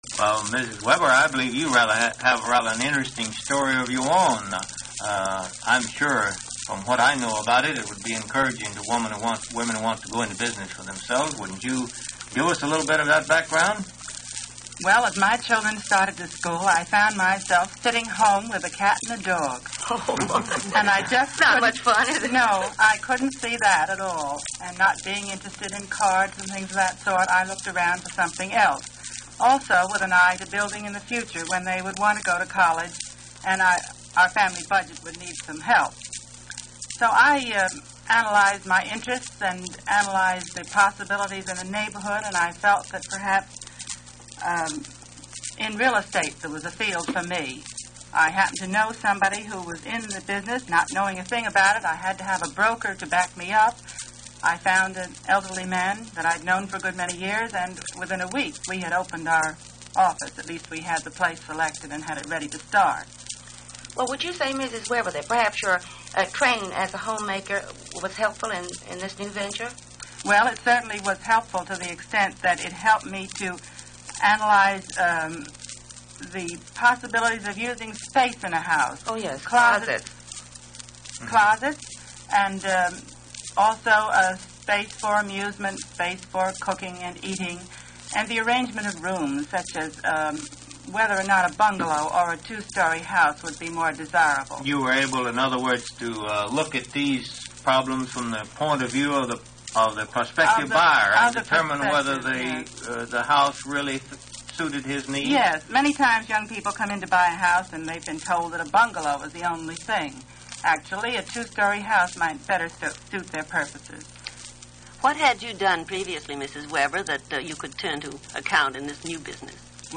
1952 Real Estate Career Interview